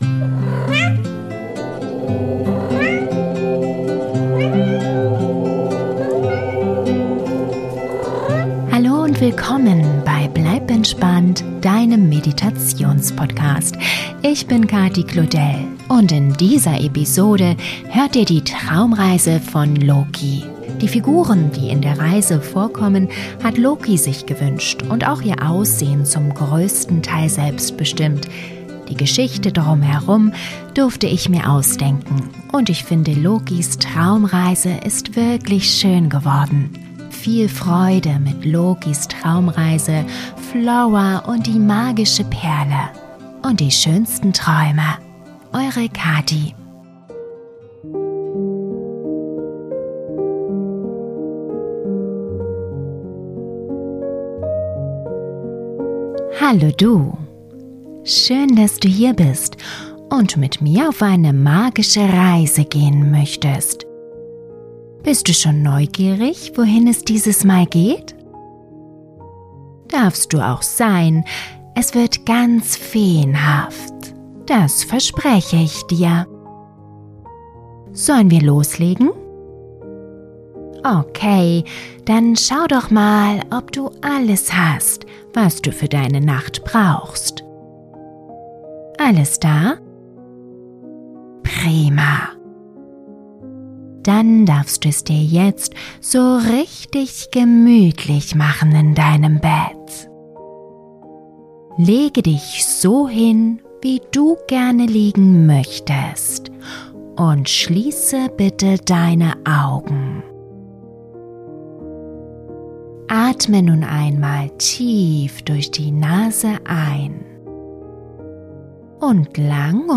Traumreise für Kinder zum Einschlafen - Flora und die magische Perle - Gute Nacht Geschichte mit Fee & Meerjungfrau ~ Bleib entspannt! Der Meditations-Podcast - magische Momente für Kinder & Eltern Podcast